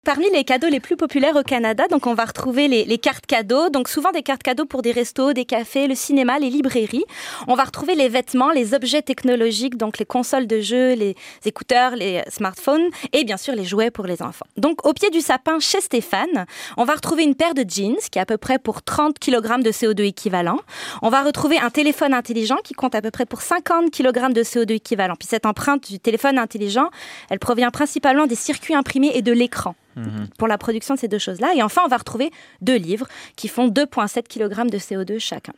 en entrevista con la radio francesa de Radio Canadá